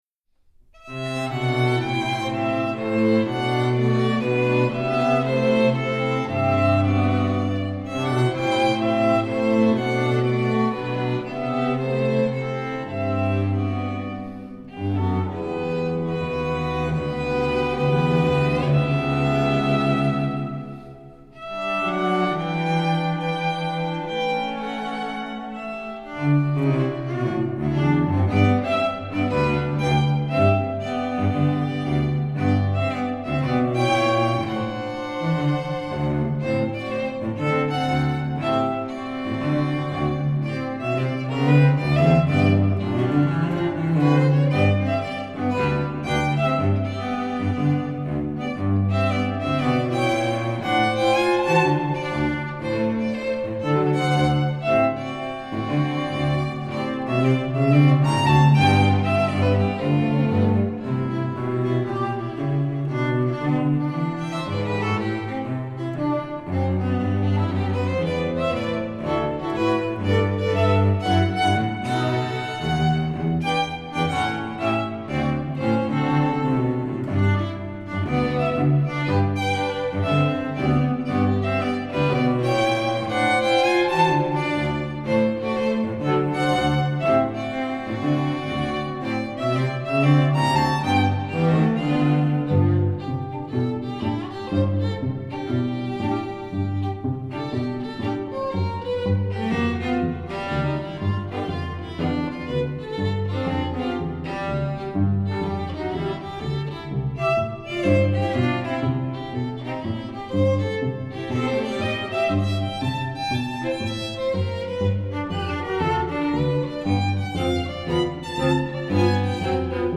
Voicing: St Quartet